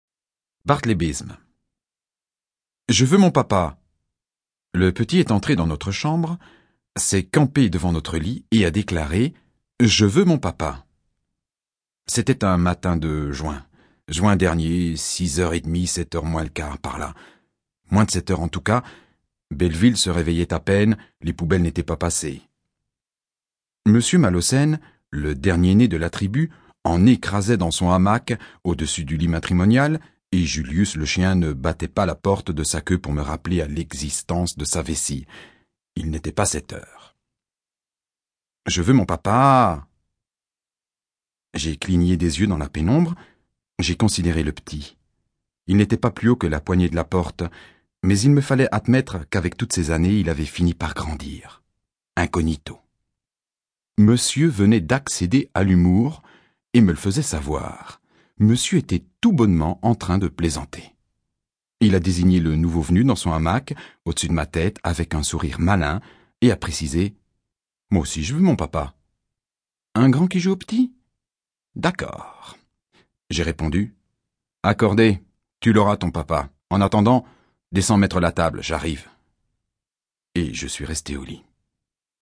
Das Hörbuch zum Sprachen lernen.Ungekürzte Originalfassung / Audio-CD + Textbuch + CD-ROM
Interaktives Hörbuch Französisch